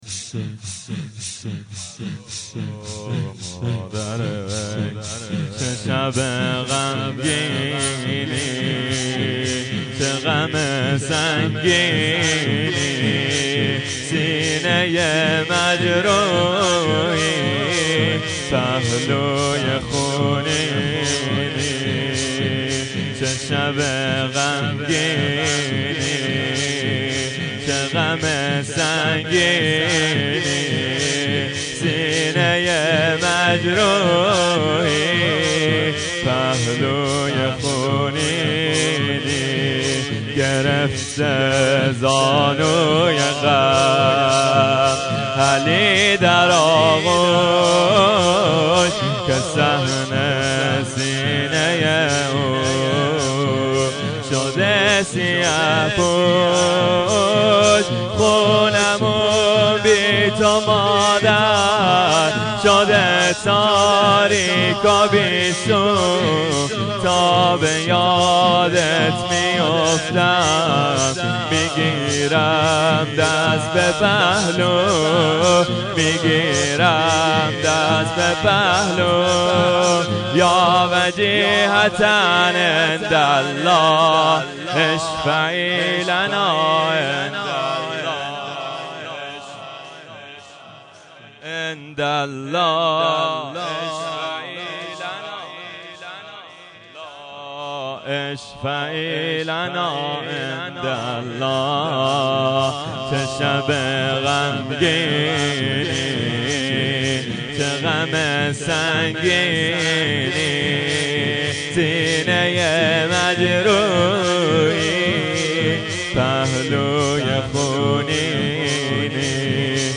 روضه حضرت زهرا سلام الله علیها